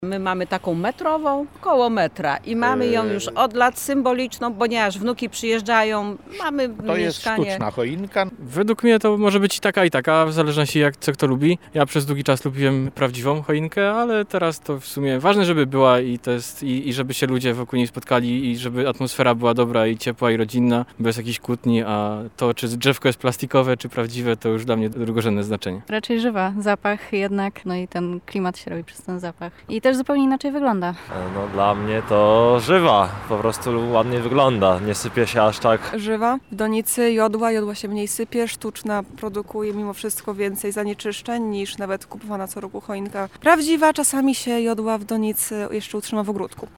Tu pojawia się odwieczne pytanie –  sztuczna czy żywa? Zapytaliśmy o to mieszkańców Lublina:
sonda